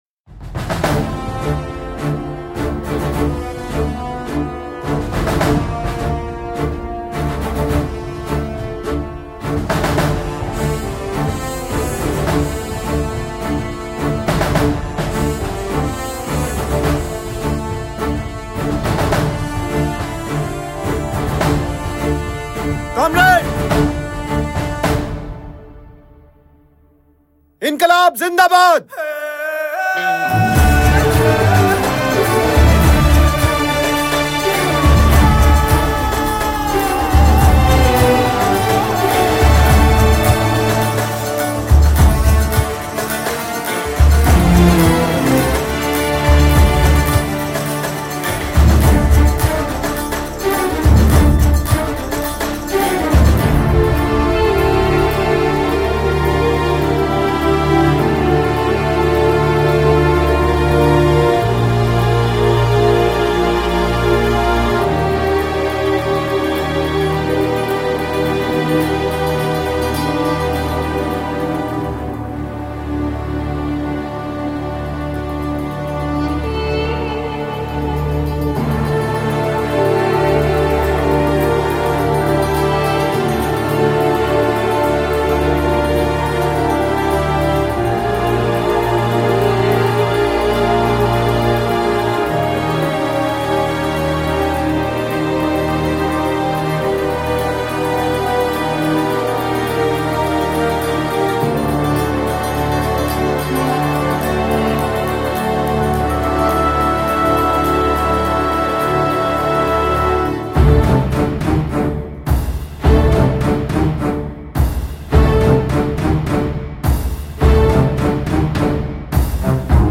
Free Download Instrumental